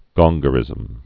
(gŏnggə-rĭzəm)